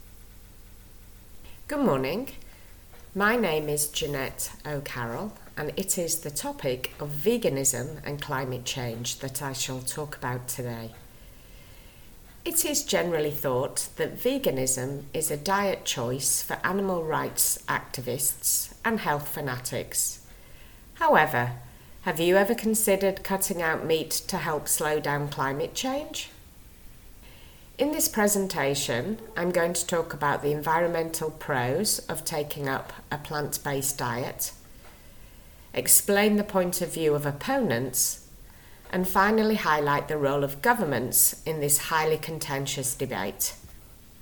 • Exam-ready recorded monologue (MP3)
c1-eoi-monologue-veganism-and-climate-change-sneak-peek.mp3